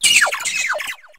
sovereignx/sound/direct_sound_samples/cries/wiglett.aif at a502427a7f5cc8371a87a7db6bb6633e2ca69ecb